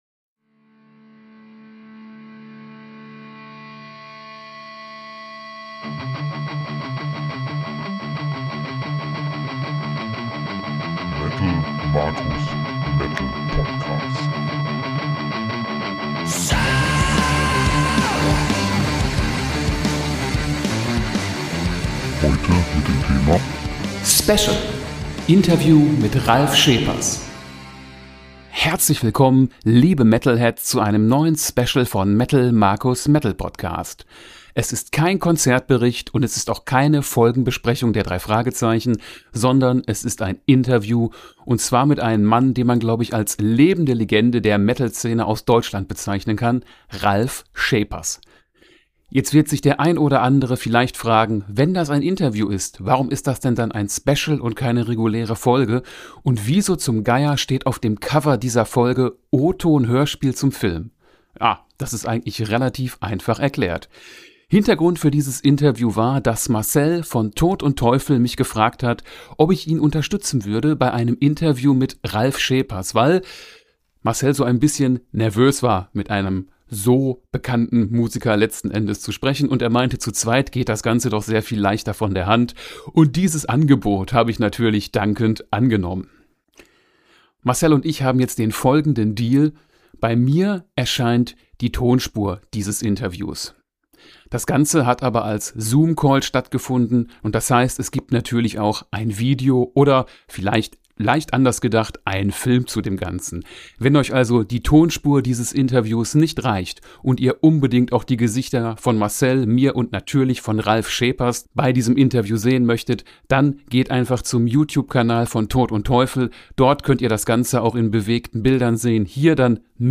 Special: Interview mit Ralf Scheepers